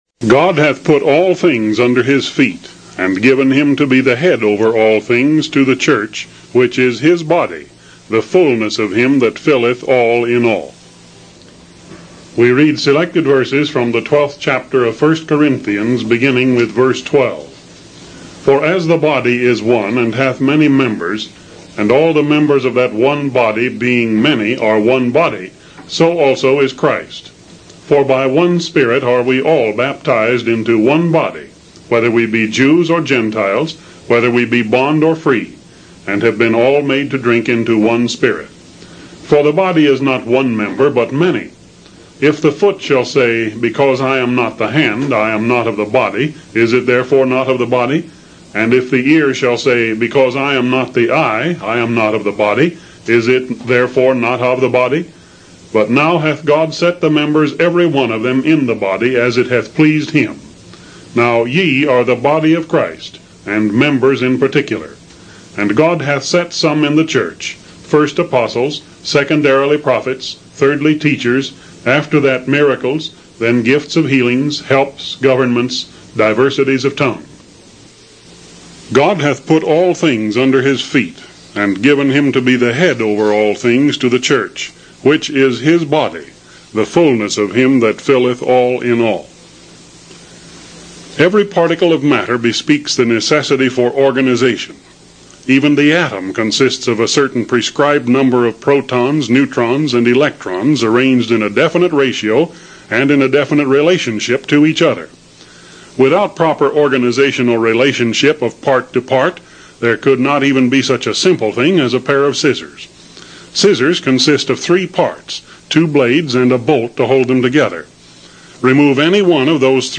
16 minute sermon